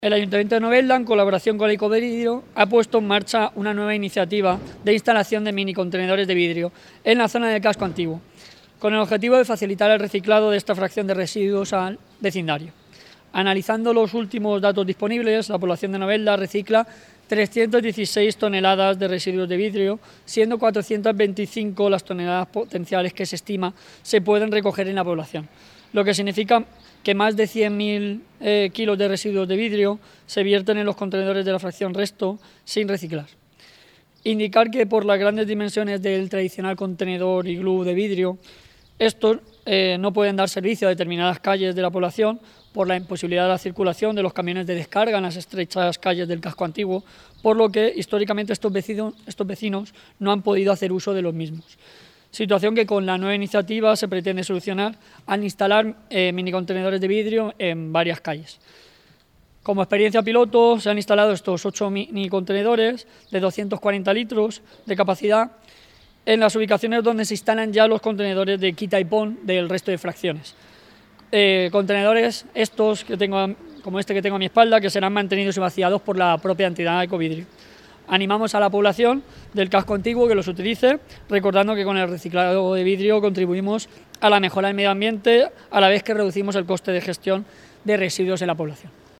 Corte-concejal.mp3